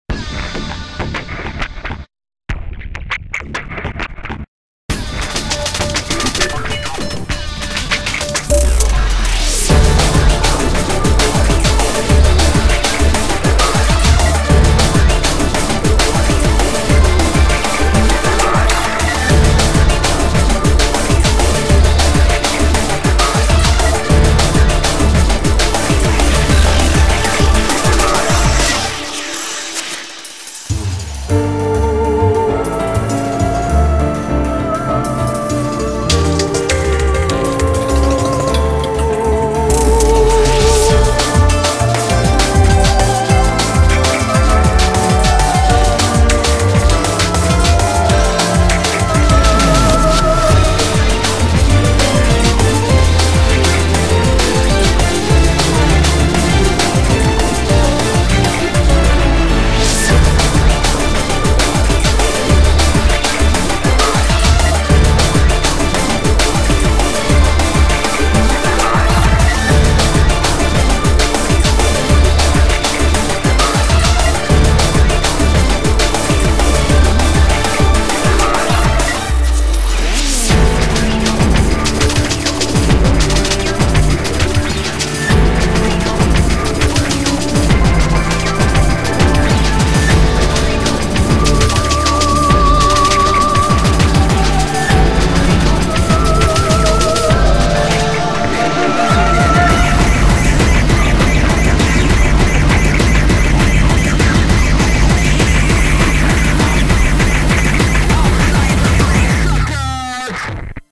BPM100-400
Comments[DRUM N BASS]